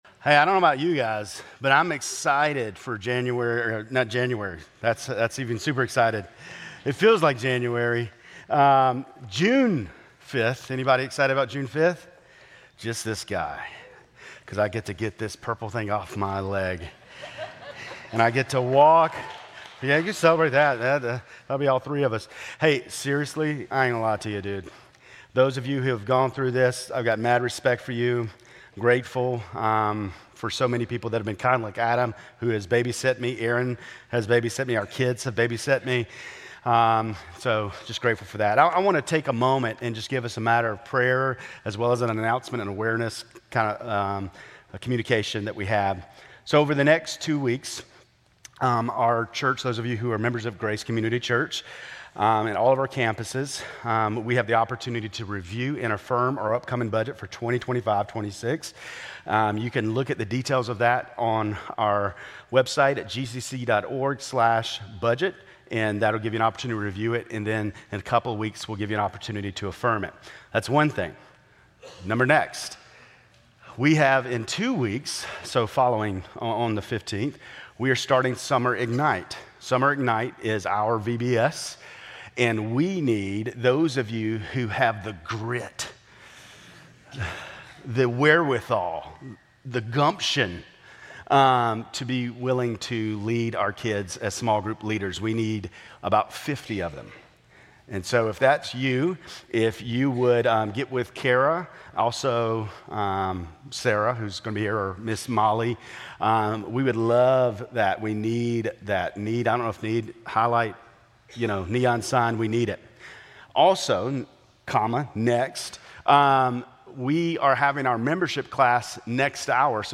Grace Community Church Lindale Campus Sermons 6_11 Lindale Campus Jun 02 2025 | 00:31:17 1x 00:00 / 00:31:17 Subscribe Share RSS Feed Share Link Embed